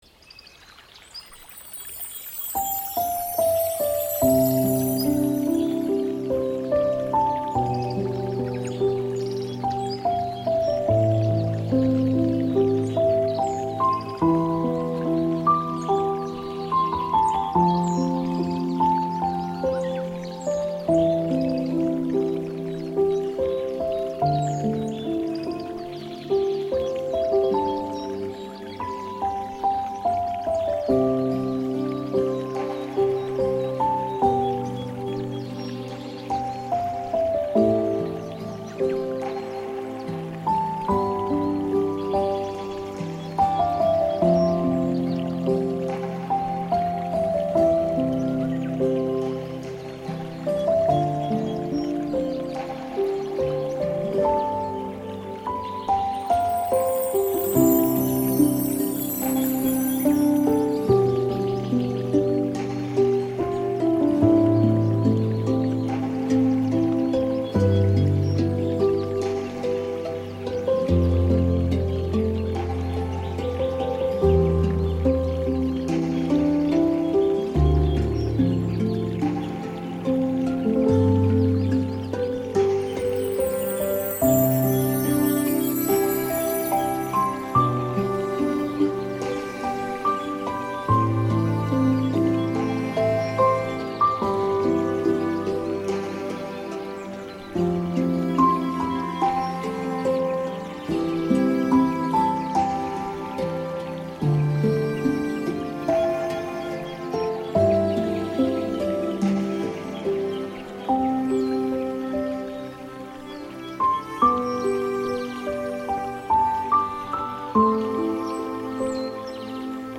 MAGISCHES DUNKELHEIT-FLÜSTERN: Schlafzauber-Flüstern mit Regen